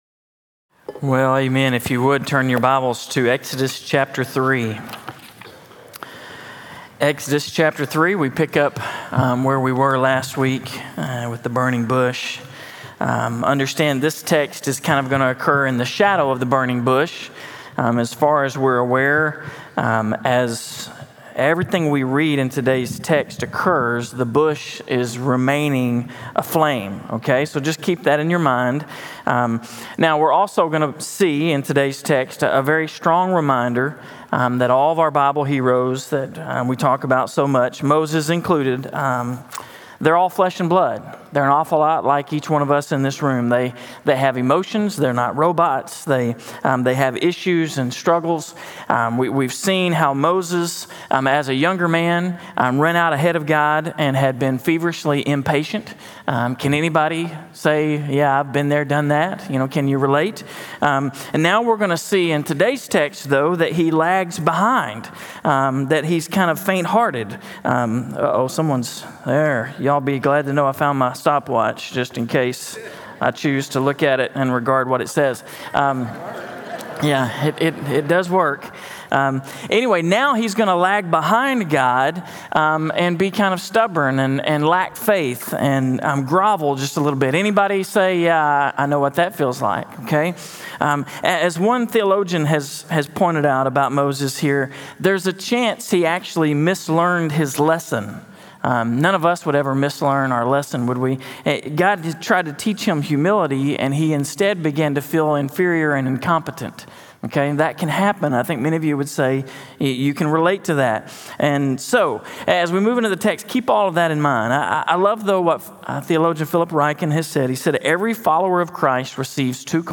In this message, "The Skepticism of Moses," from Exodus 3:11-22, we move into week seven of our sermon series, “The Deliverer.” We are reminded that walking with God requires faith, and even Moses was entirely human, filled with doubts and questions.